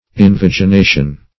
Invagination \In*vag`i*na"tion\, n. [L. pref. in- + vagina